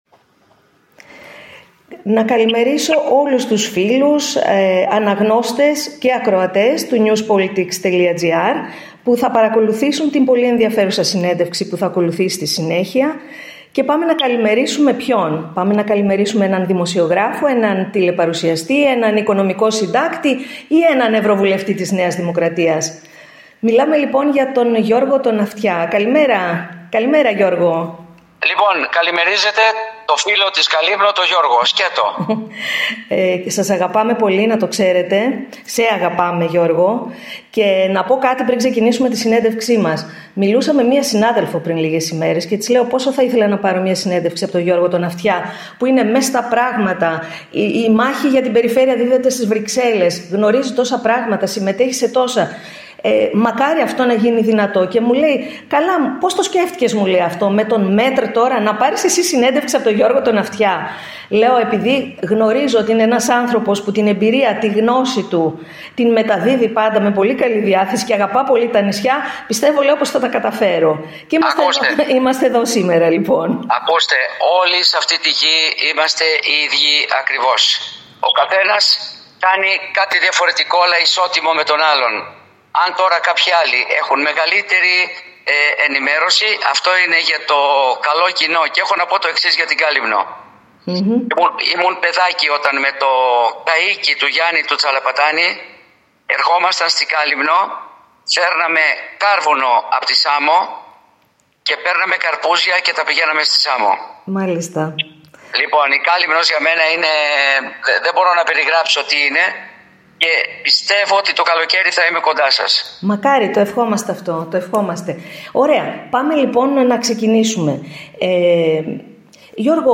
Από την πρώτη στιγμή, η συζήτηση είχε έντονο συναισθηματικό αποτύπωμα.
Μίλησε με αγάπη και συγκίνηση για τον τόπο, με τον οποίο τον συνδέουν βιώματα από τα νεότερα χρόνια του, ενώ άφησε ανοιχτό το ενδεχόμενο να βρεθεί στο νησί το φετινό καλοκαίρι.